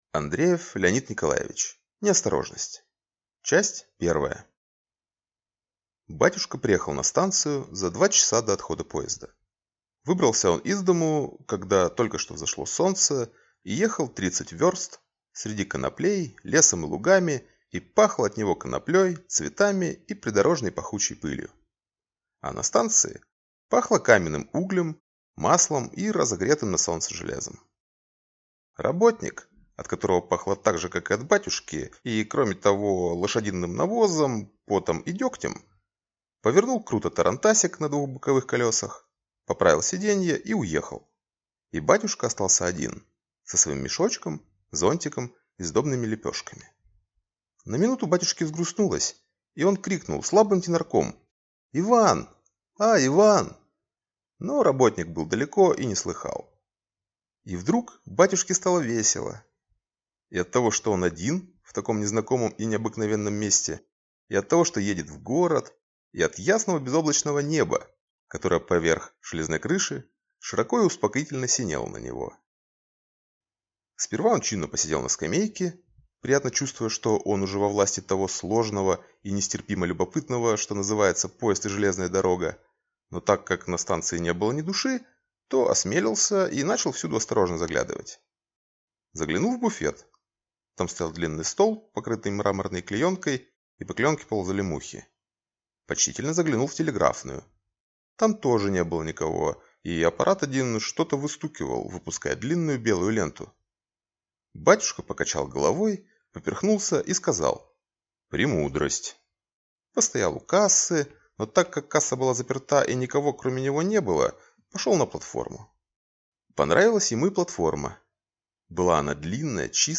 Аудиокнига Неосторожность | Библиотека аудиокниг